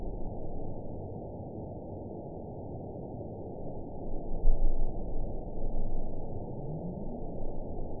event 920062 date 02/21/24 time 00:36:16 GMT (2 months, 1 week ago) score 9.51 location TSS-AB06 detected by nrw target species NRW annotations +NRW Spectrogram: Frequency (kHz) vs. Time (s) audio not available .wav